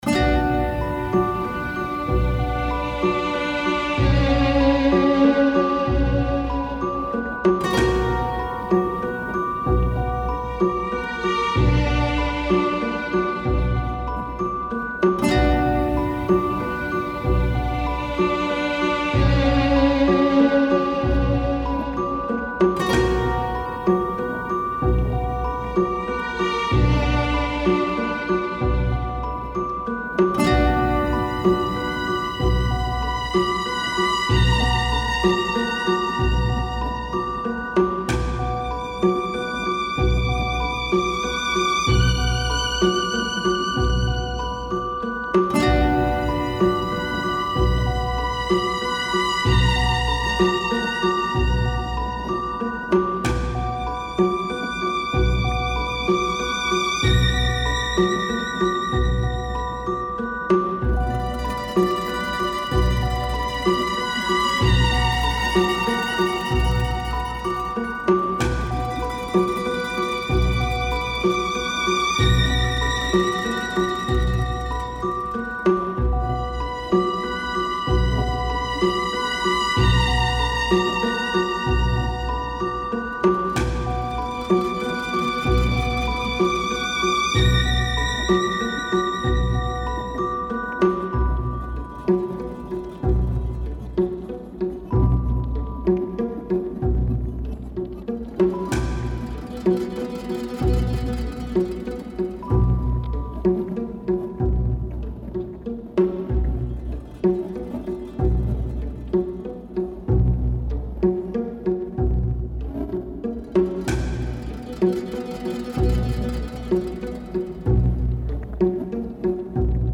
何かが起こりそうな雰囲気のBGM
シネマチック 4:07